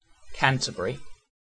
Ääntäminen
Ääntäminen UK UK : IPA : /ˈkantəb(ə)ɹi/ Haettu sana löytyi näillä lähdekielillä: englanti Käännöksiä ei löytynyt valitulle kohdekielelle. Määritelmät Erisnimet A cathedral city in Kent , England .